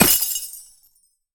ice_spell_impact_shatter_01.wav